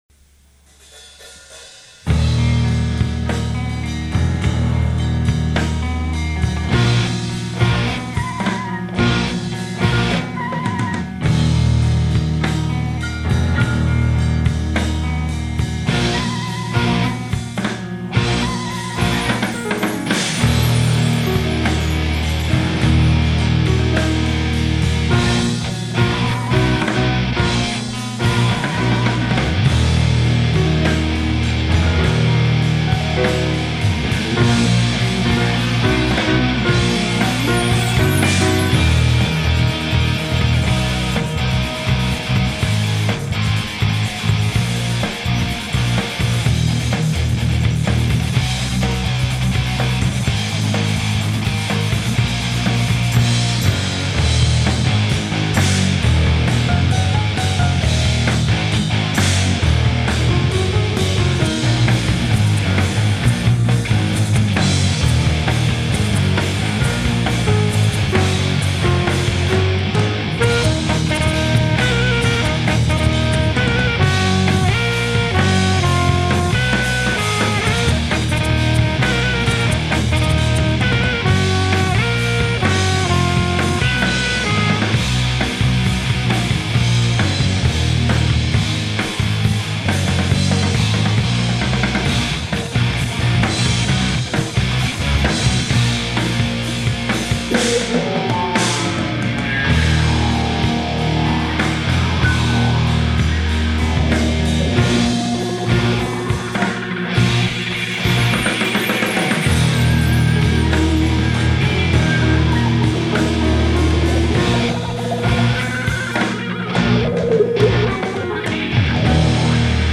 rough mix after 8 days